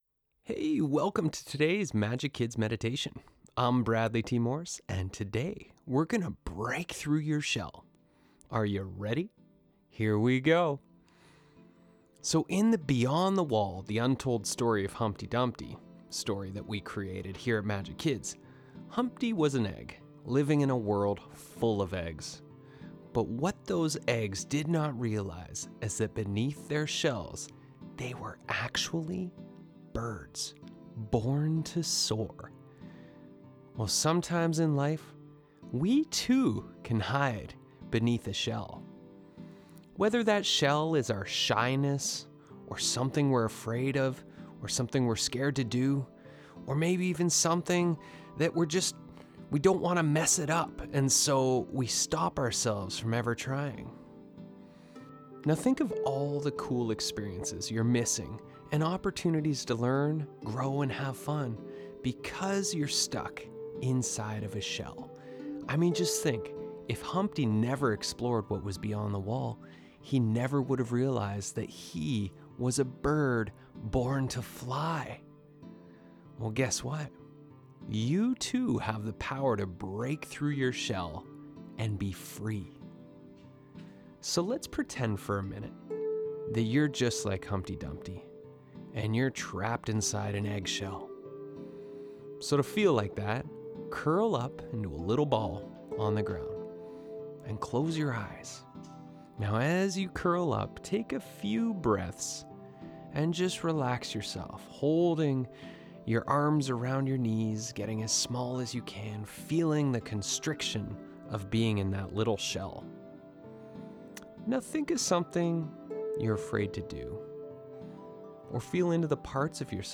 Guided Meditation
Breaking-Through-Your-Shell-Majik-Kids-Meditation.mp3